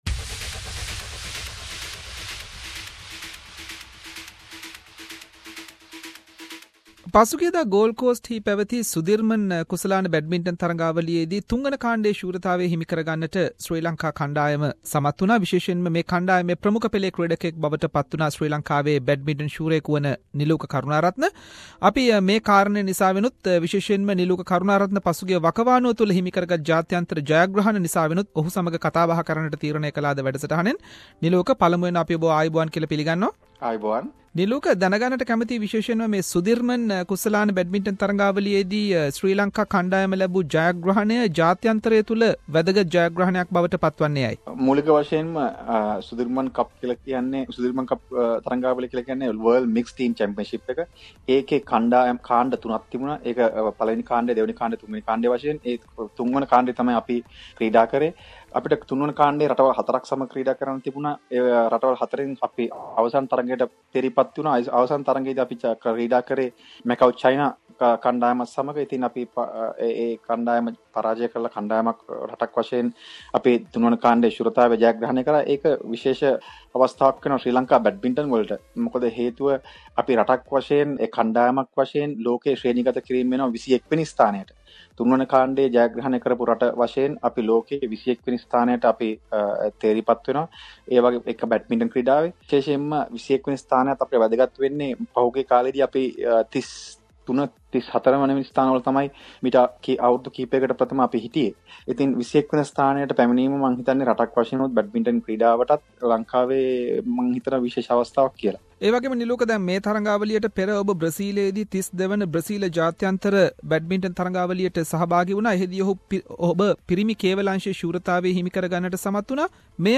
Sri Lankan badminton players has won the group 3 championship at Sudirman cup badminton tournament held in Gold Coast Australia recently. Prominent Sri Lankan Badminton player Niluka Karunaratne joined with SBS Sinhalese to talk about this achievement and Sri Lanka badmintons future plans.